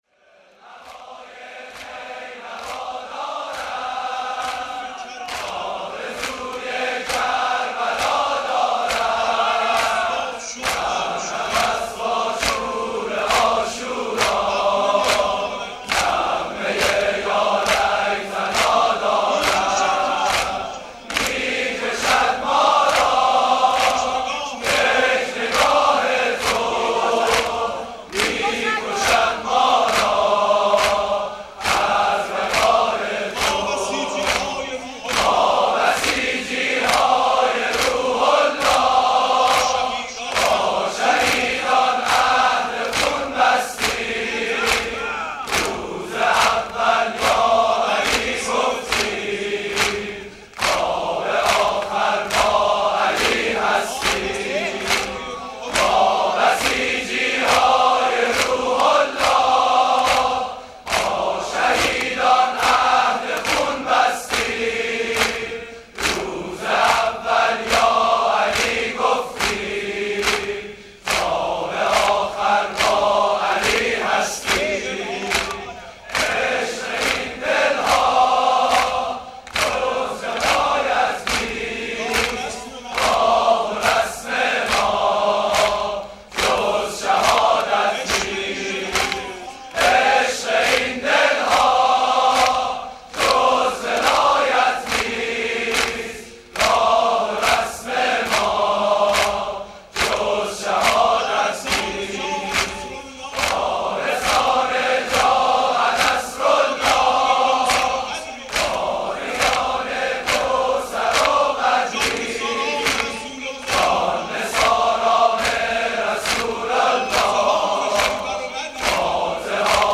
دم پایانی